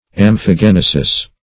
Search Result for " amphigenesis" : The Collaborative International Dictionary of English v.0.48: Amphigenesis \Am`phi*gen"e*sis\, n. [Gr.